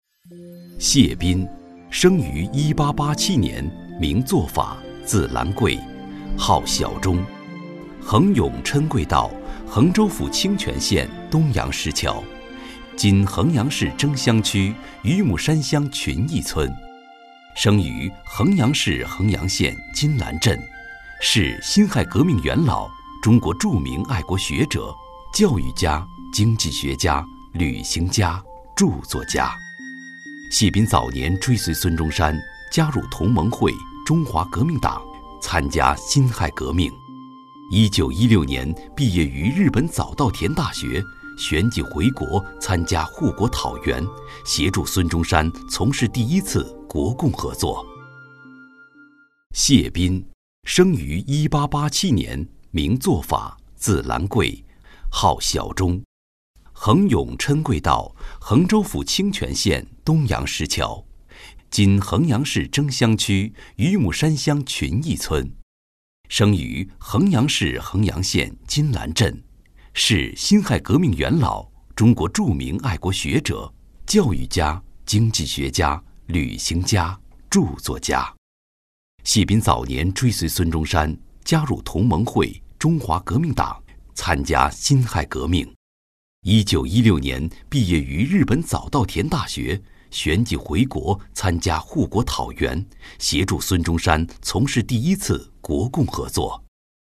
磁性解说